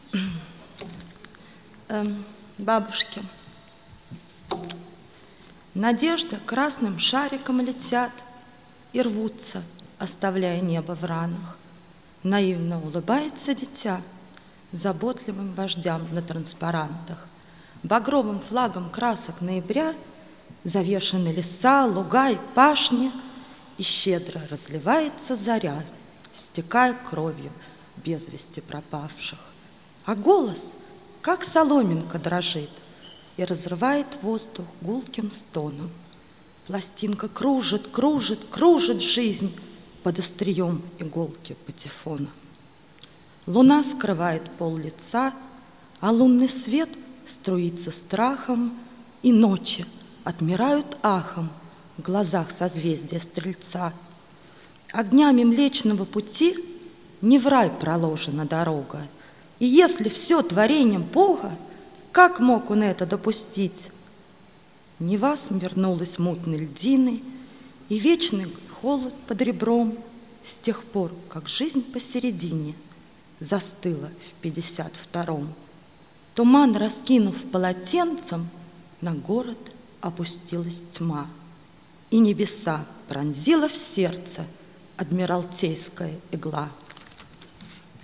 16.07.2005, Липки.
Выступление на поэтическом слёте.
Ты заметно волнуешься, когда читаешь.
Я, действительно, вдруг разволновалась неожиданно.